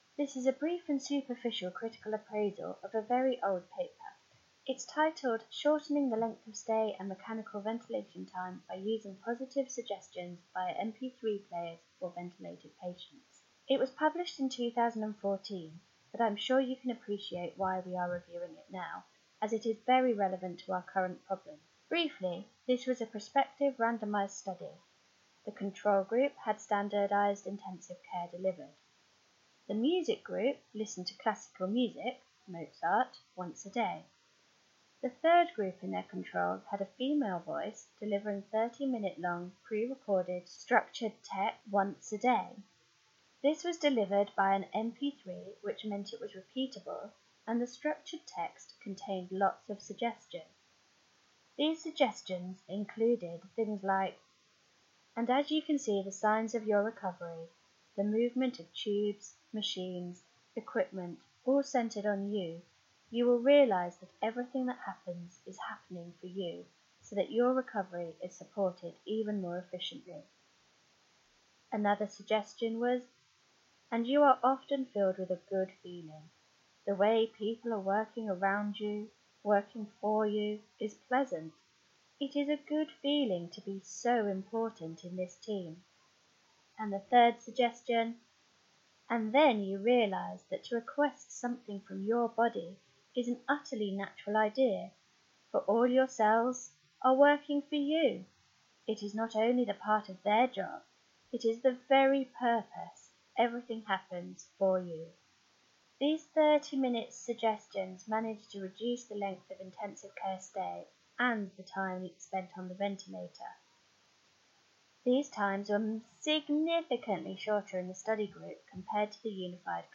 Review of Article.mp3